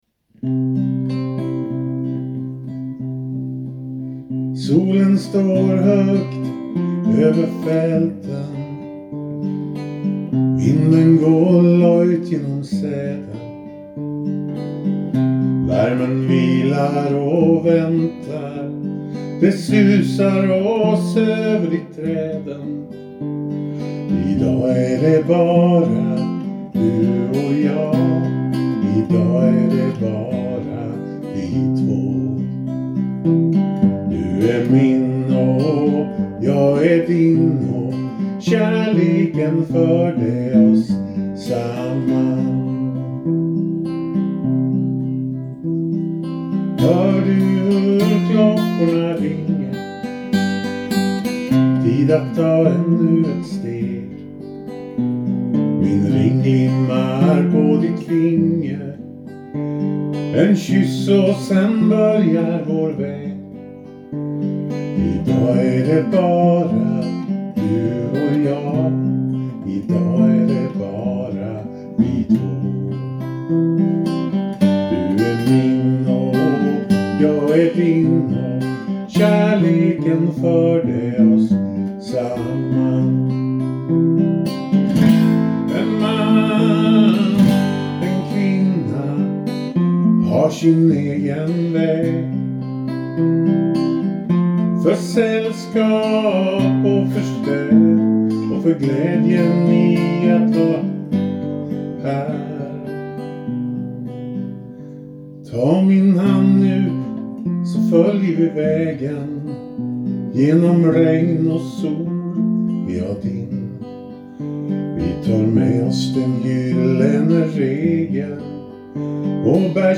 framfört på gitarr